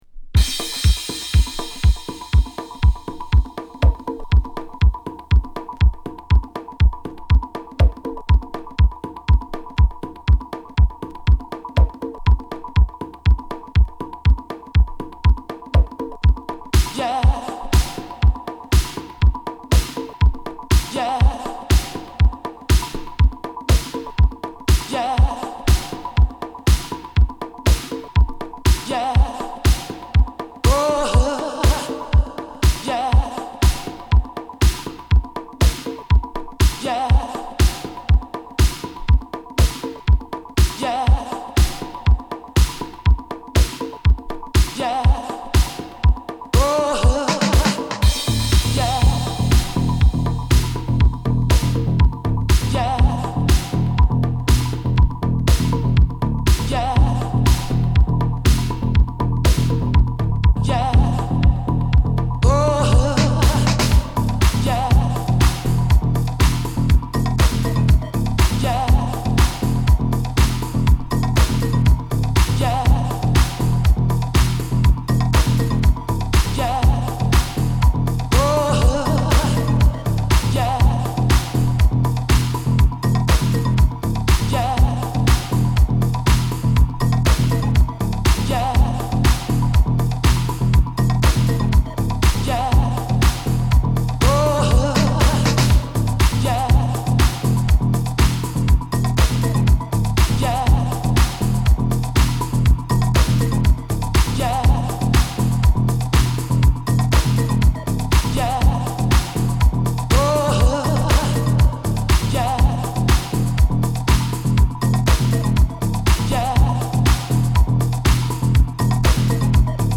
Acid House , Beatdown , Disco